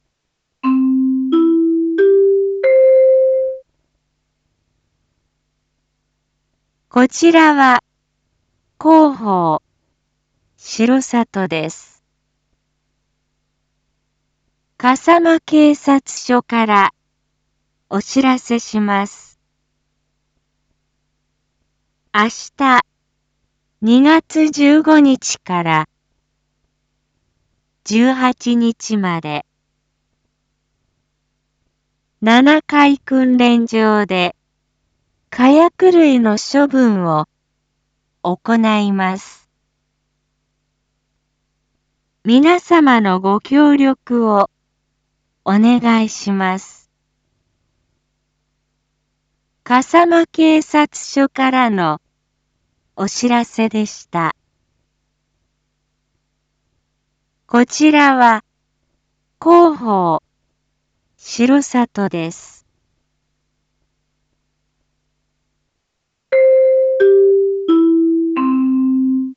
BO-SAI navi Back Home 一般放送情報 音声放送 再生 一般放送情報 登録日時：2022-02-14 19:06:10 タイトル：2/14 19時② 放送分 インフォメーション：こちらは広報しろさとです。